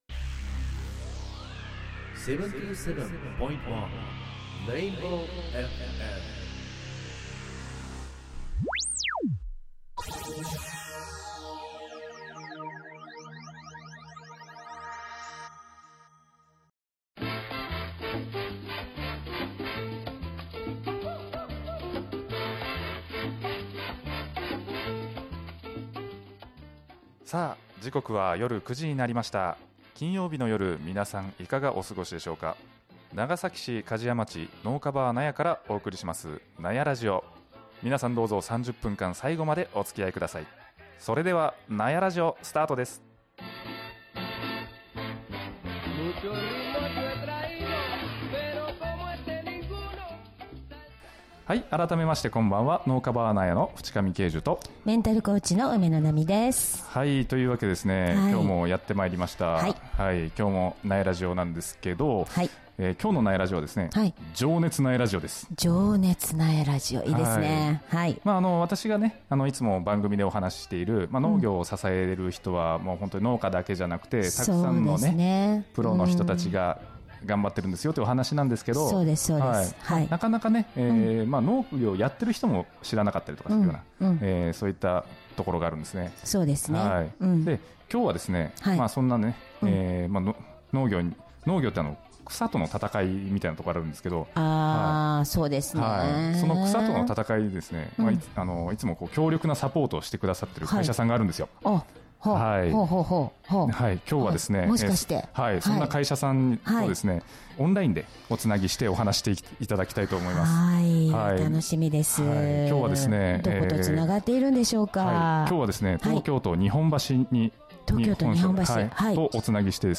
新企画「情熱ナヤラジオ」は農業を支える様々なプロフェッショナルに話を聞く新感覚農業ドキュメンタリー。 第１回目は日産化学株式会社農業化学品事業部のみなさんをオンラインゲストにお招きします。